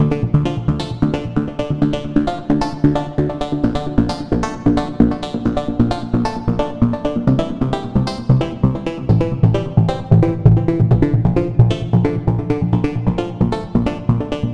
描述：艰难的狂欢合成器循环
Tag: 132 bpm Rave Loops Synth Loops 2.45 MB wav Key : Unknown